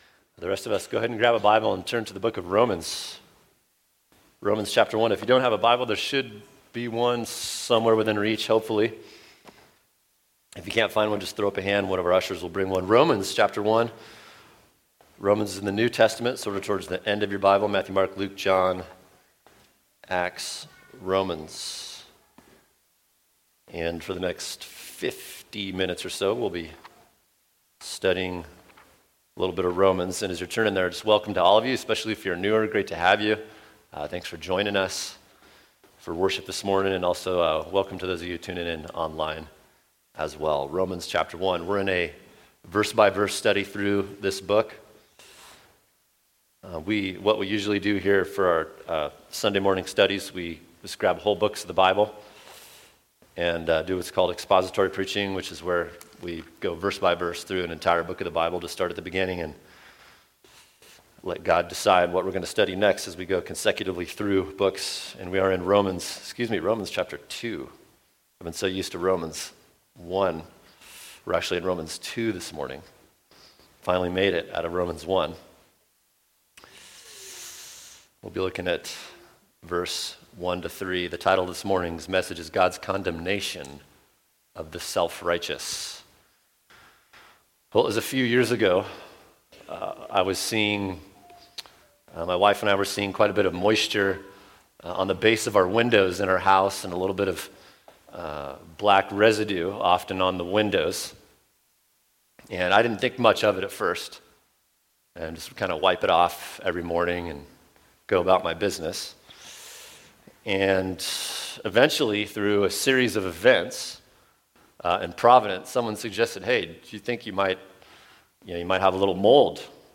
[sermon] Romans 2:1-3 God’s Condemnation of the Self-Righteous – Part 1 | Cornerstone Church - Jackson Hole